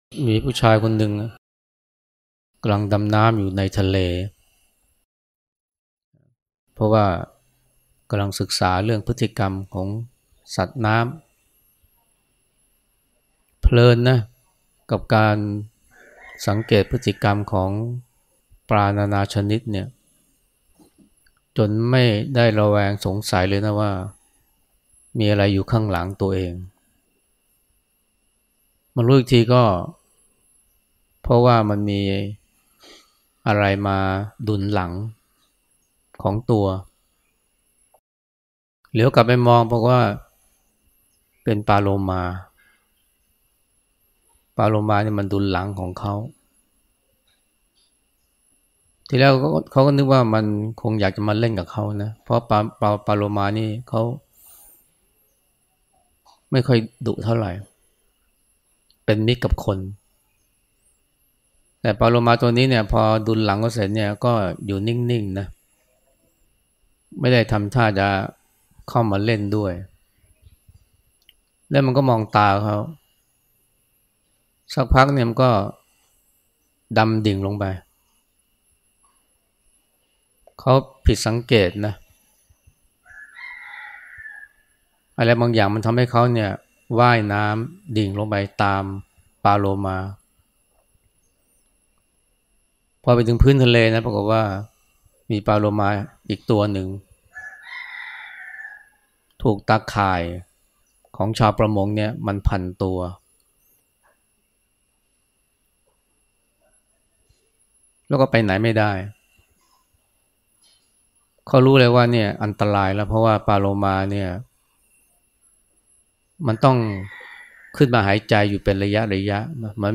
พระอาจารย์ไพศาล วิสาโล แสดงธรรมก่อนฉันเช้าวันที่ 25 สิงหาคม 2567 มีผู้ชายคนหนึ่ง กำลังดำน้ำอยู่ในทะเล เพราะว่ากำลังศึกษาเรื่องพฤติกรรมของสัตว์น้ำ เพลินกับการสั ...